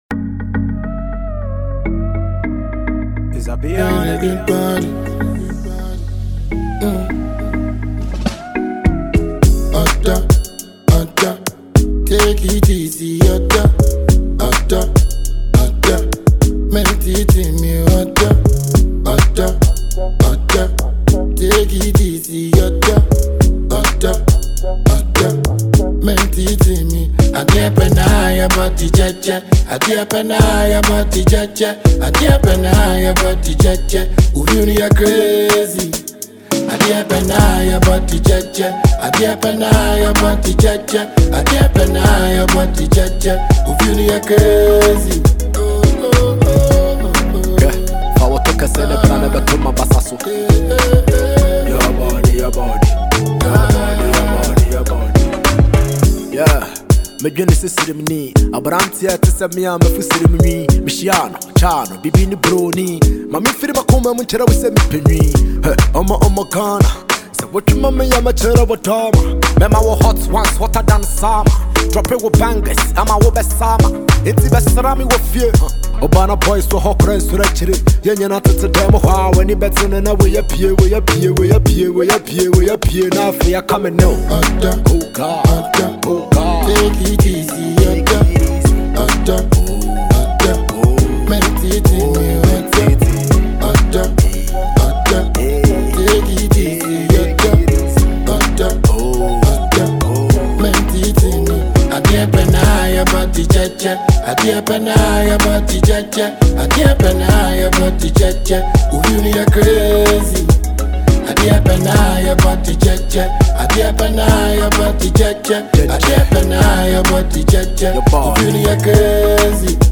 a versatile singer and rapper